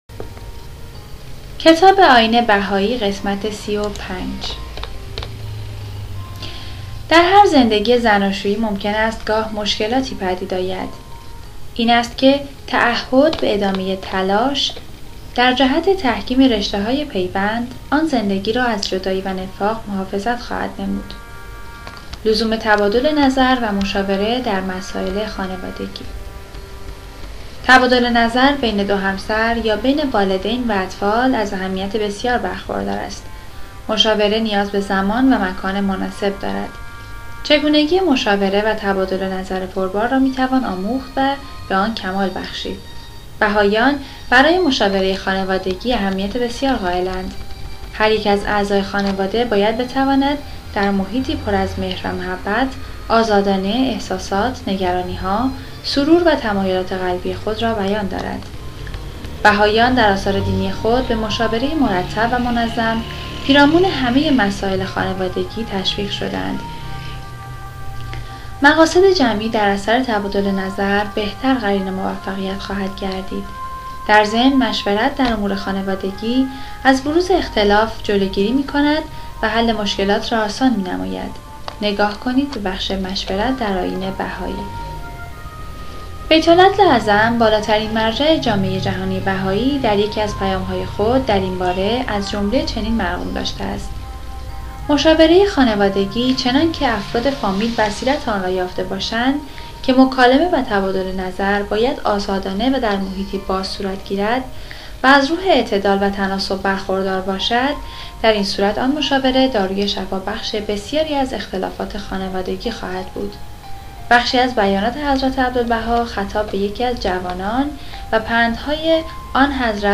کتاب صوتی «نگرشی کوتاه به تاریخ و تعالیم دیانت بهائی» | تعالیم و عقاید آئین بهائی